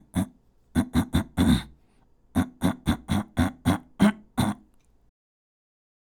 まずは、「ん」の状態で、咳払いしてノイズを作ります。
※咳払いのノイズの見本音声
10_kaseitai_noise_sekibarai.mp3